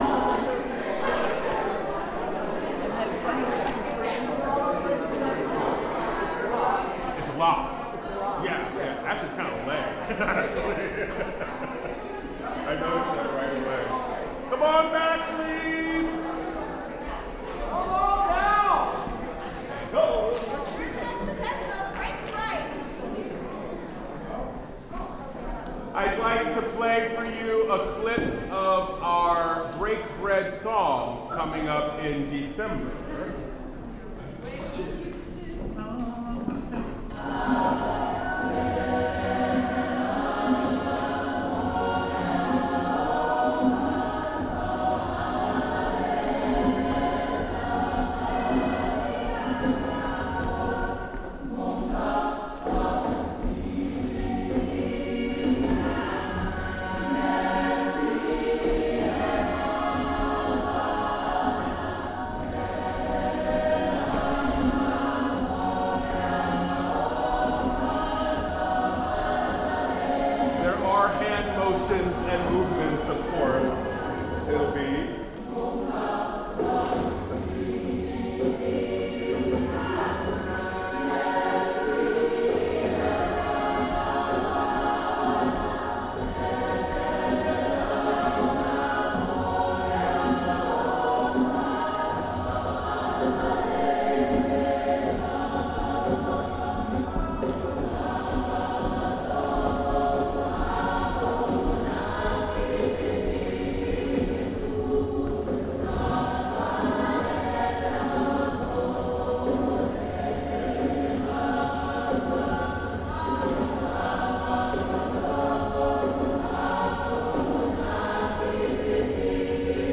The recording starts in the middle of that.
Sang more solfege, beginning with major scales in solfege, with hand gestures. Also talks on choral techniques and intonation.